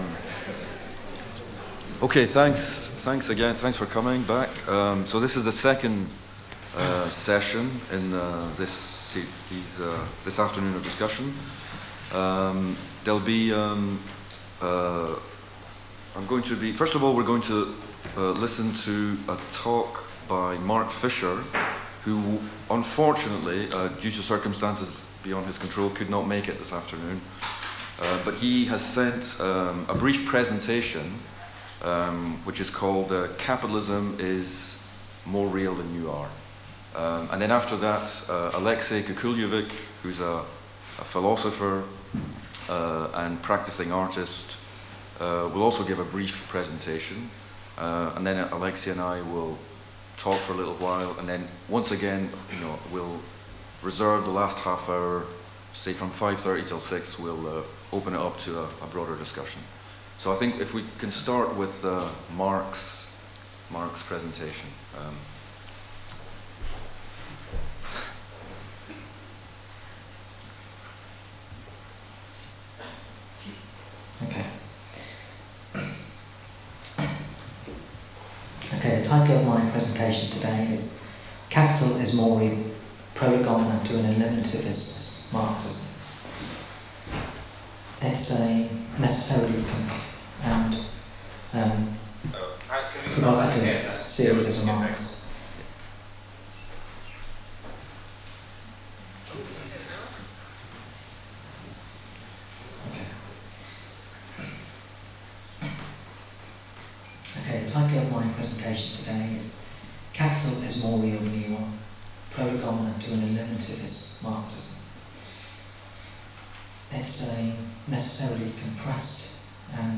A conversation, to which we hope you will join in; and which might broadly address itself to how neoliberal capitalism has locked down social experience.